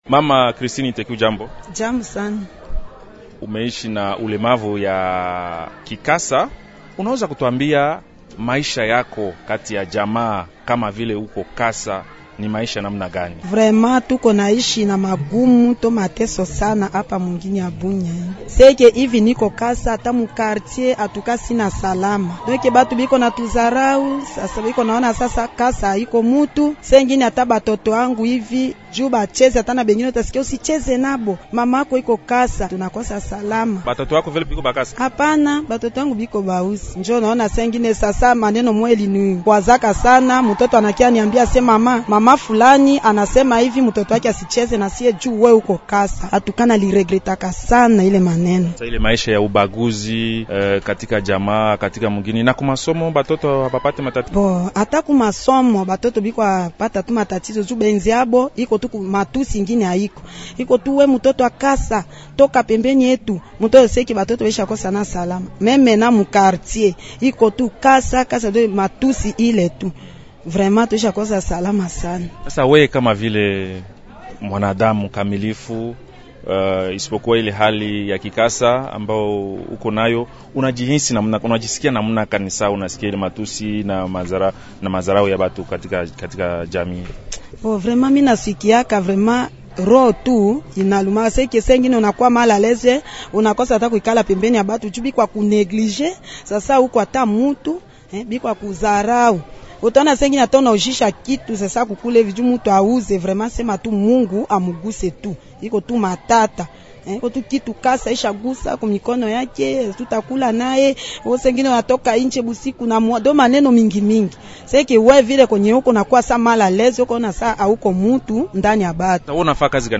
Anasema yeye ni mhanga wa dharau na ubaguzi wa kila aina. Aliyasema hayo mjini Bunia, katika maadhimisho ya Siku ya Kimataifa ya Watu Wanaoishi na Ulemavu.